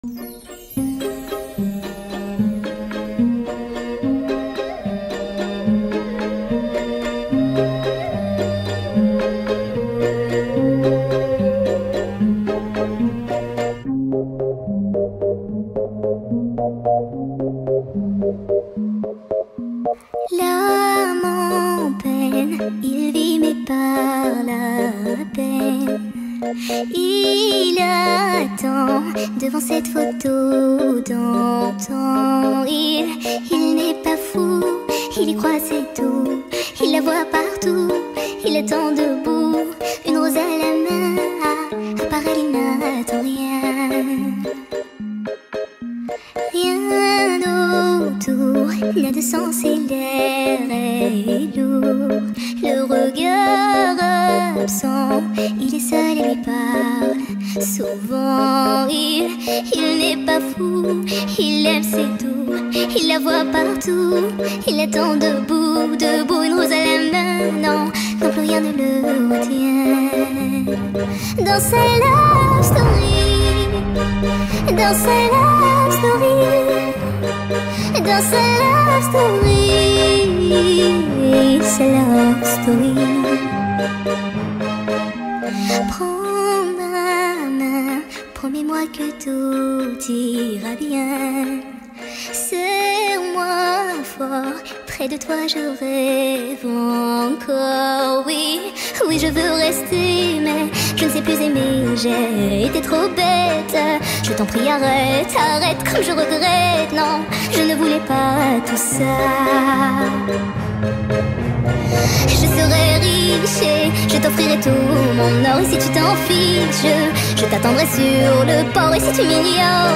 دانلود نسخه Sped Up و سریع شده آهنگ عاشقانه
عاشقانه